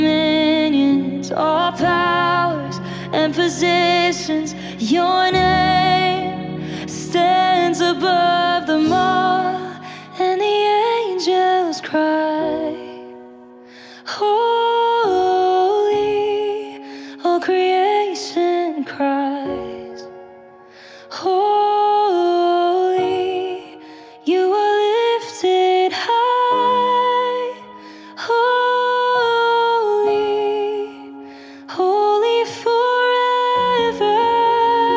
# Devotional & Spiritual